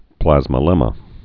(plăzmə-lĕmə)